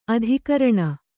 ♪ adhikaraṇa